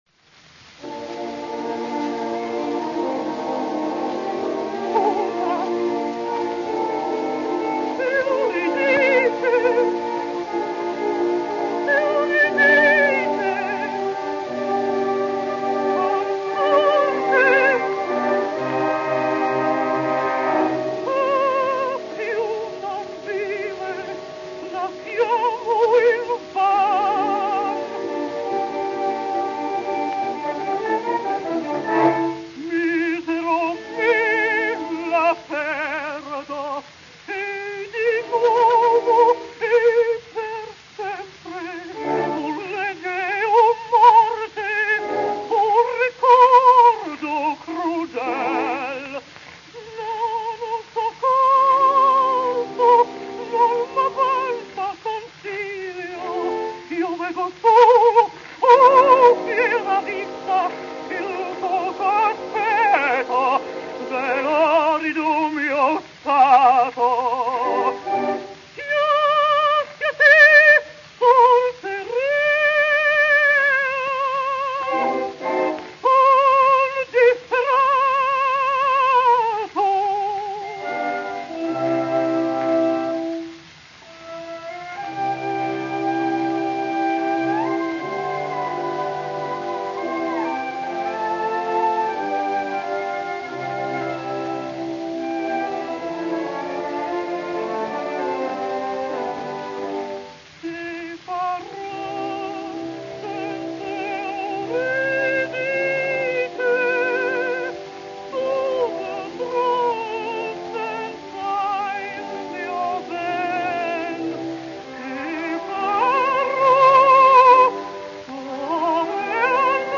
English contralto, 1872 - 1936
Clara Butt possessed one of the greatest and most powerful contralto voices, ranging from C below middle C to high B flat (Sir Adrian Boult remembers a rehearsal when she sang through four B flats with ease). There was a vast and “baritonal” bottom and a lighter toned pure top.
Her true contralto voice, however, is one of the most glorious ever recorded.